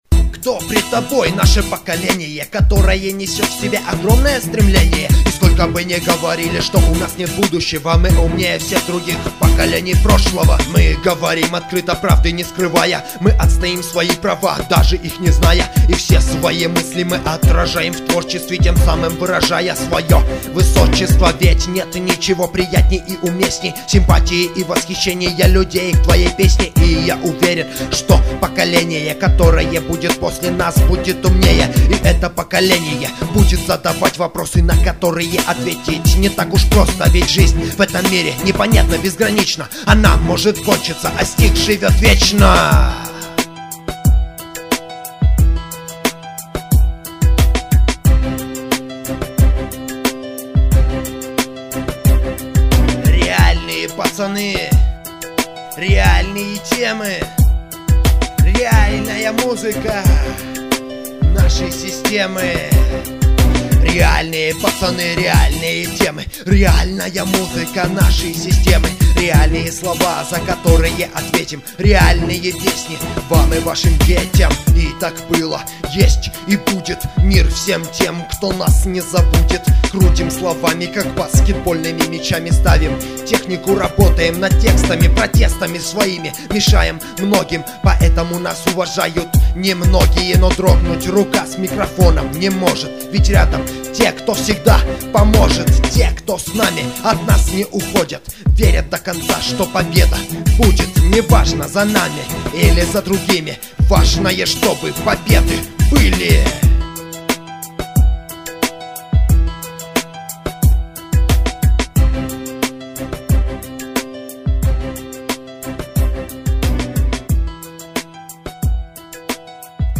undeground rap